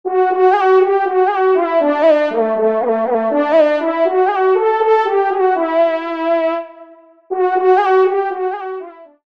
FANFARE
Extrait de l’audio « Ton de Vènerie »